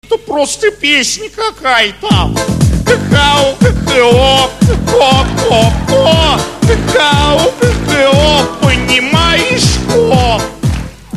песня-пародия